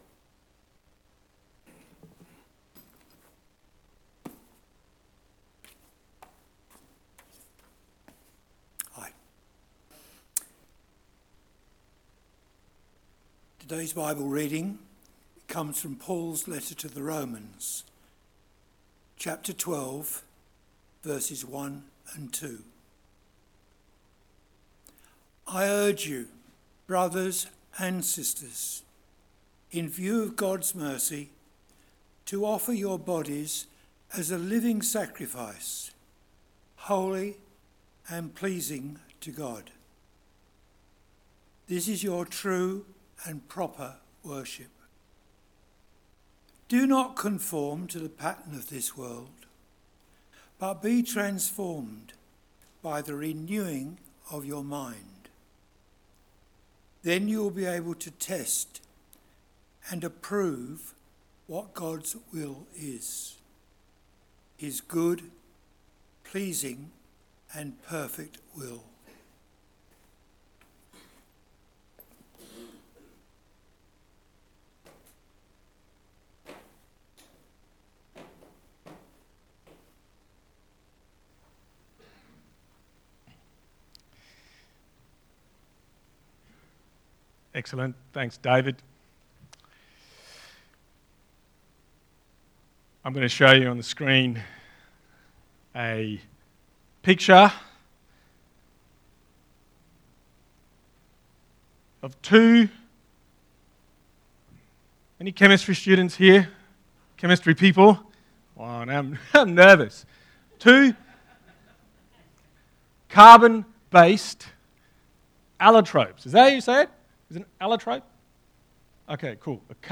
Text: Romans 12: 1-2 Sermon